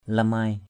/la-maɪ/ (cv.) limai l{=m 1.
lamai.mp3